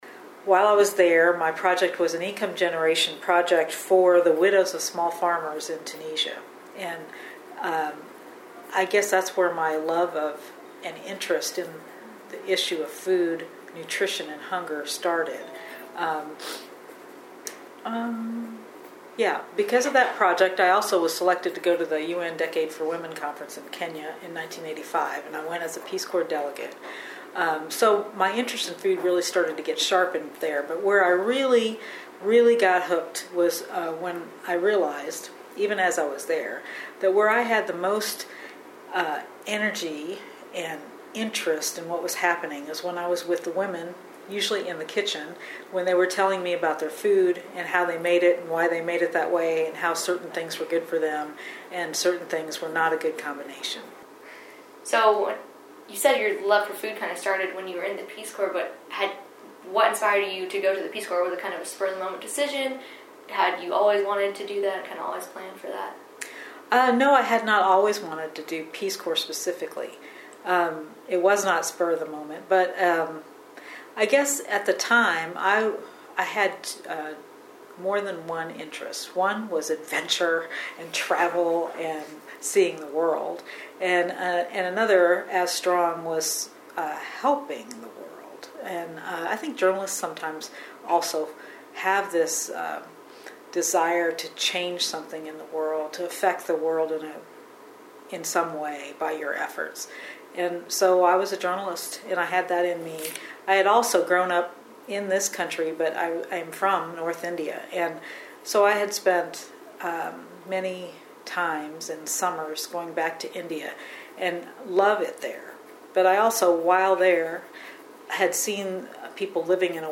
Interview
Aired on KBIA. Battling Hunger: Interviews from the Universities Fighting World Hunger Summit HOME Writing Clips Multimedia Photography Graphic Design Ethics More Use tab to navigate through the menu items.